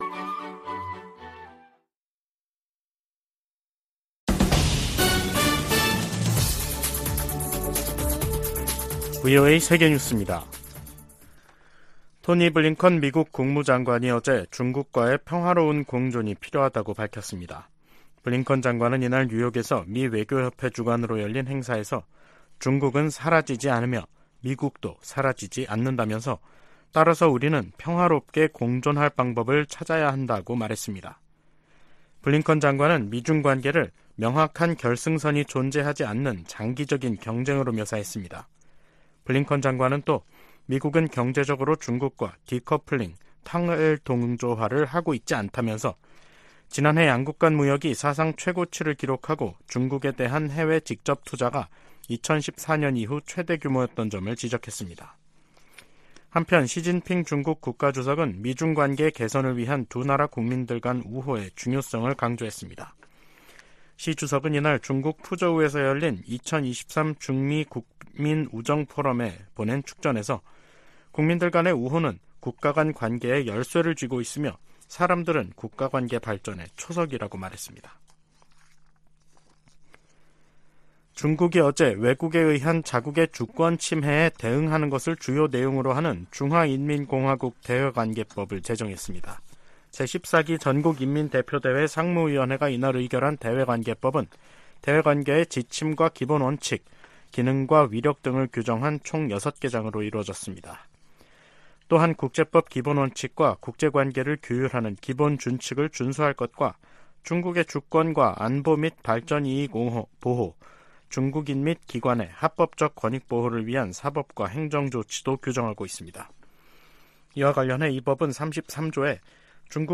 VOA 한국어 간판 뉴스 프로그램 '뉴스 투데이', 2023년 6월 29일 2부 방송입니다. 커트 캠벨 백악관 국가안보회의(NSC) 인도태평양 조정관은 미한일 정상회담에서 3국 협력 가능 방안을 모색할 것이라고 말했습니다. 북한은 인신매매를 정부 정책으로 삼고 있는 최악의 인신매매 국가라고 국무부 고위관리가 지적했습니다. 토니 블링컨 미 국무장관은 중국과 평화적 공존 방안을 모색해야 한다며, 이를 위해 동맹·파트너와 협력을 강화하고 있다고 밝혔습니다.